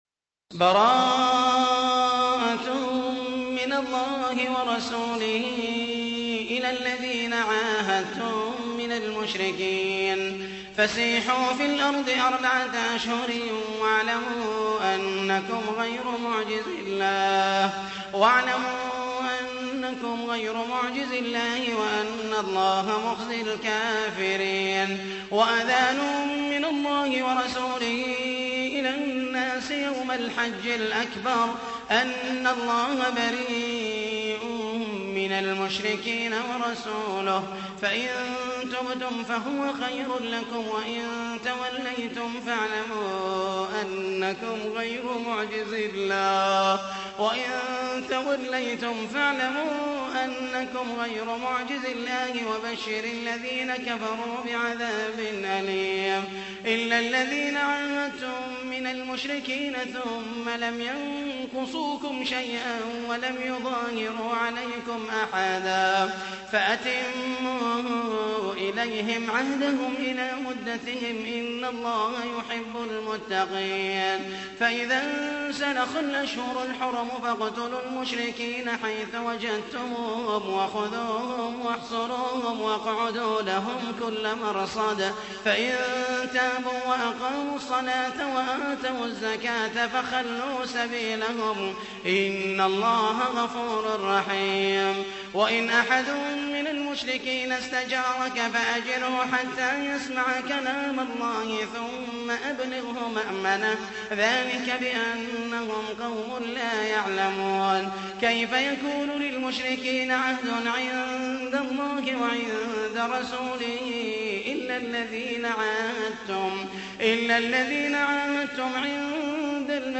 تحميل : 9. سورة التوبة / القارئ محمد المحيسني / القرآن الكريم / موقع يا حسين